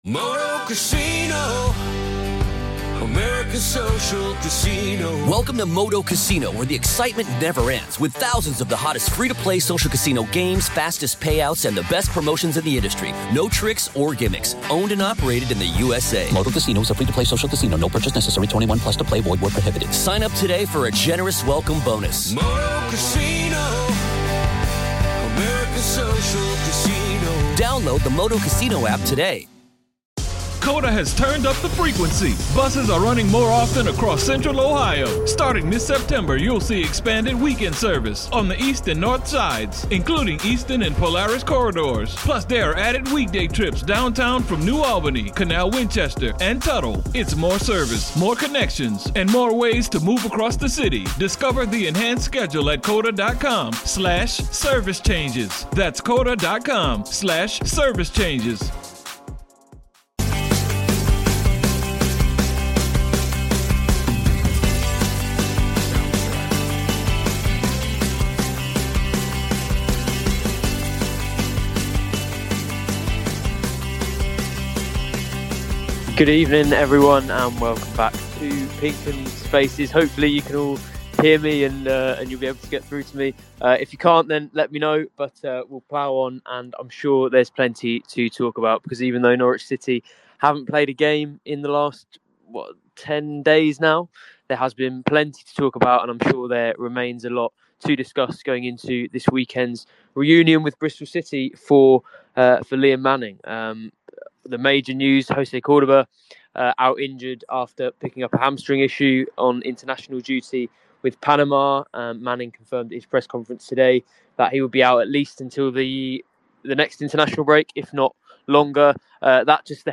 The PinkUn Norwich City Podcast / #2 What next for Norwich City?